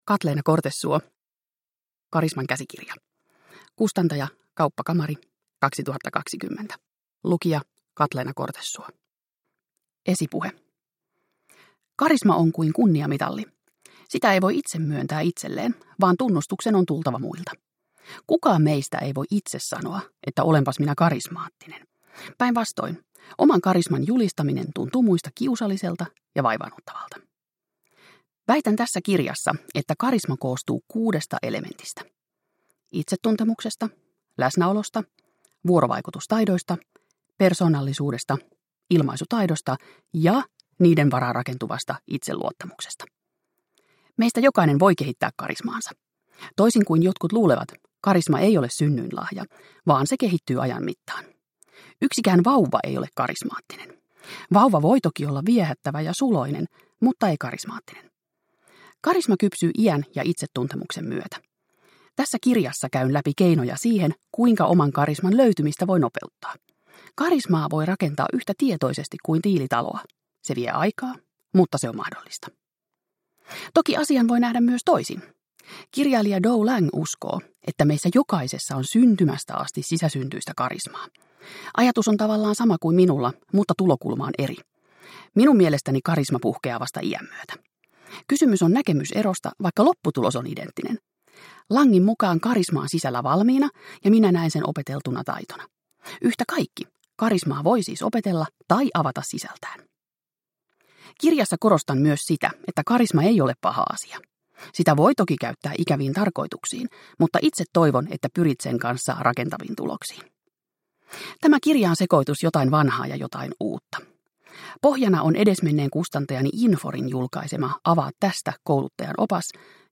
Karisman käsikirja – Ljudbok – Laddas ner